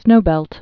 (snōbĕlt)